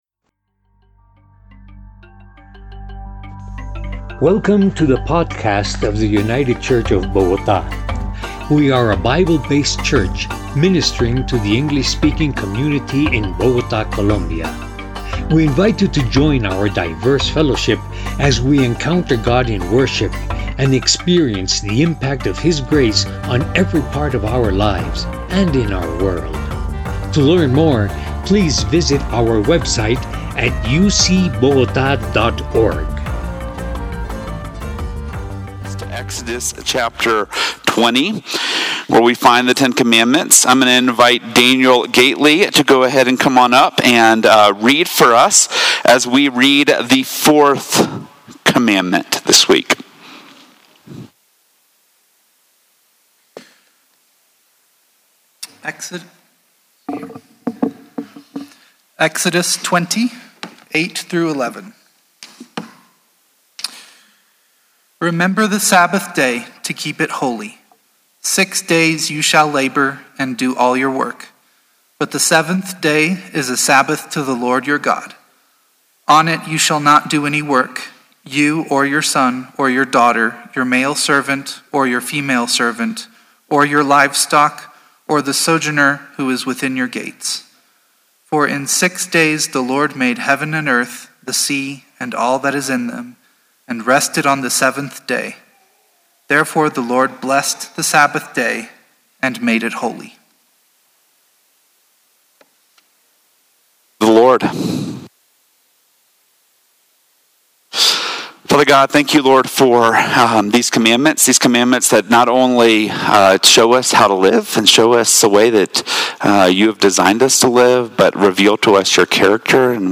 Categories: Sermons